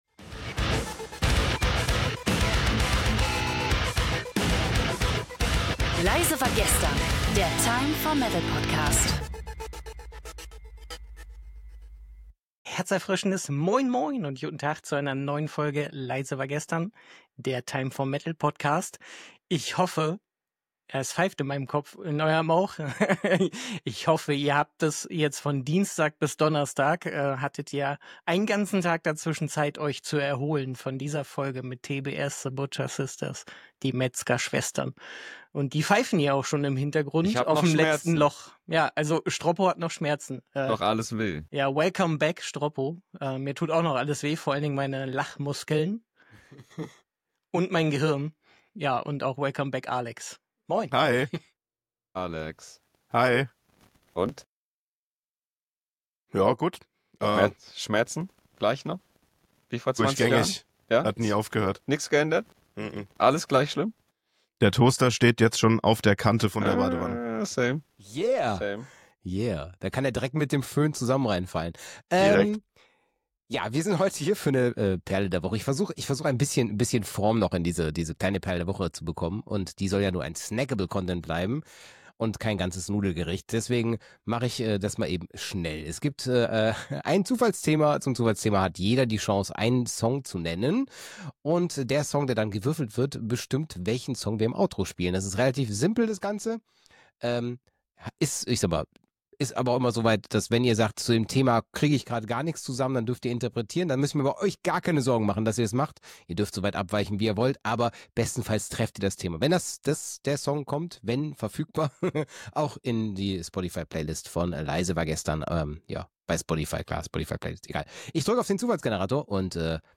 In dieser Episode starten die Gastgeber mit einem herzerfrischenden Moin Moin – und direkt mal mit einem Scherz über ihre körperlichen Wehwehchen. Bevor es musikalisch losgeht, gibt es eine Runde Selbstironie, die für viele Lacher sorgt. Doch dann dreht sich alles um die „Perle der Woche“: Das Zufallsthema dieser Folge ist Tempowechsel in Songs!